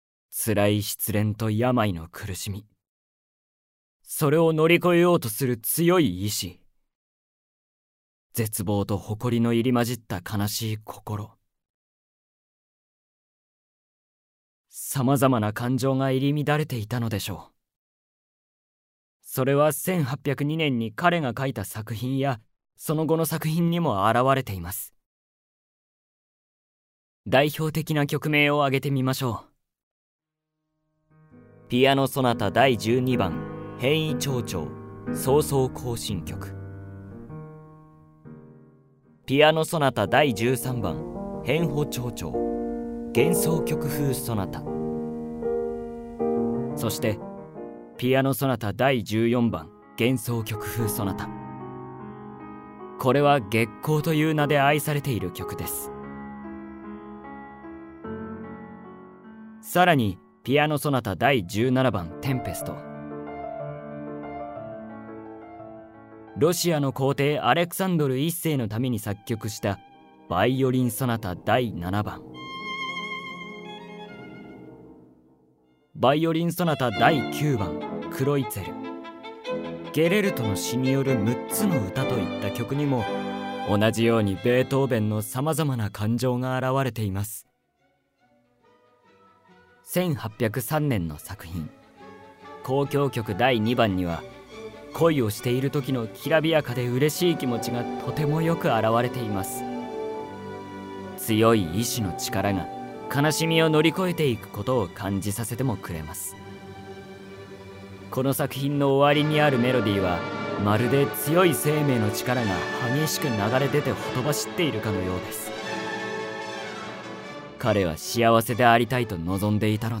[オーディオブック] ベートーヴェンの生涯（こどものための聴く名作43）